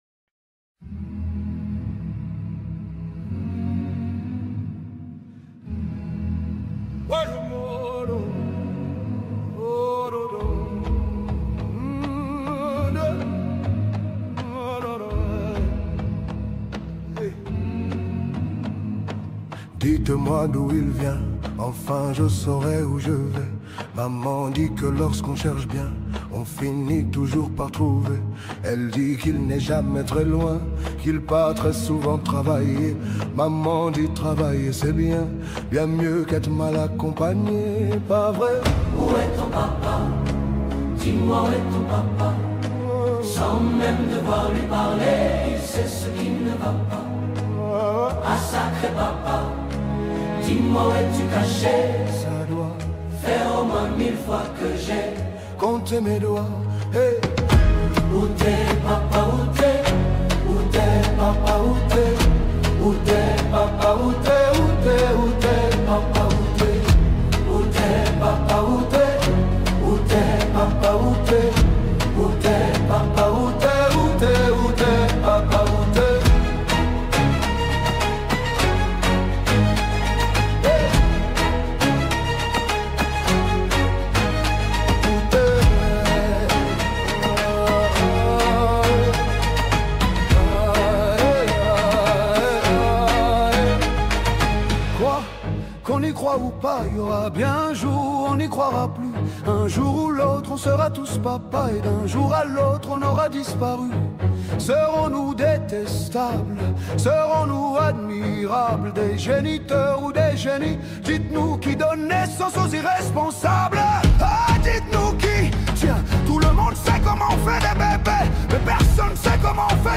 Home » DJ Mix » Amapiano
South African singer